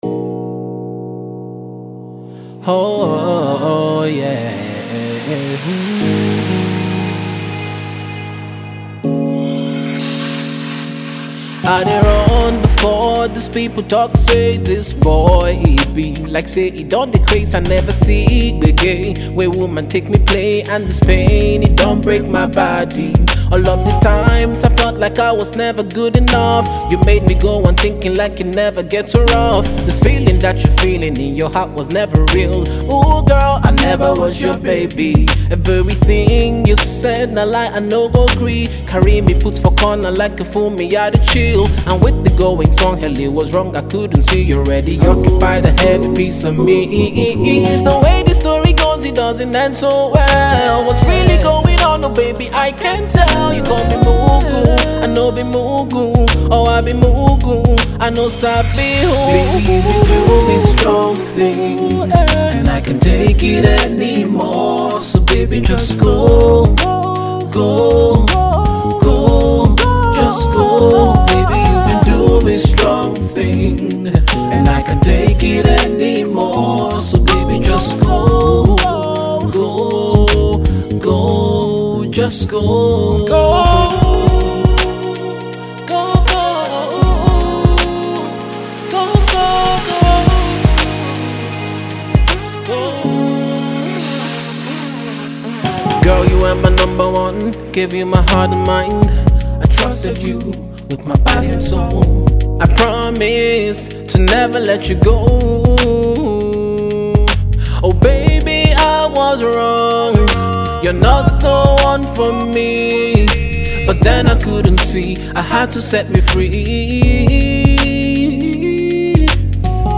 contemporary R&B sound
soulful music with an air of gloom
expressive singing